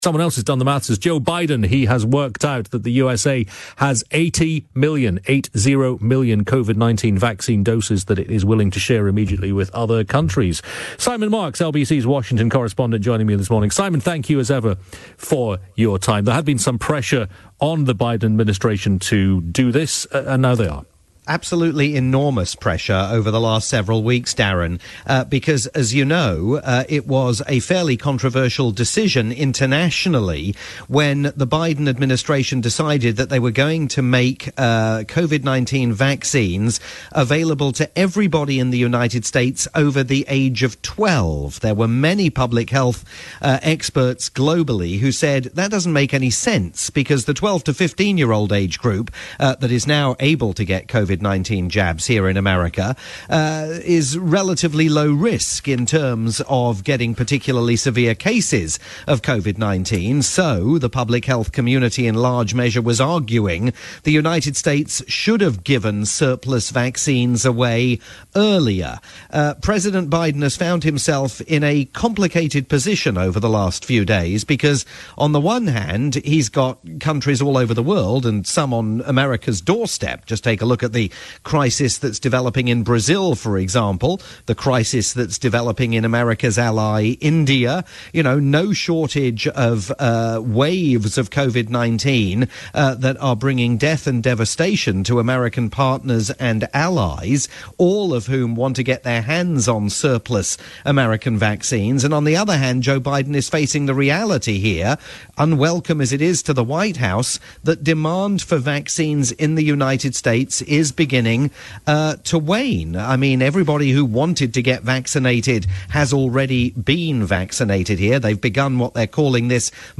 live chat
on LBC's overnight programme in the UK.